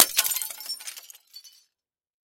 На этой странице собраны звуки, связанные с зеркалами: от едва уловимых отражений до резких ударов.
Зеркало - Другой вариант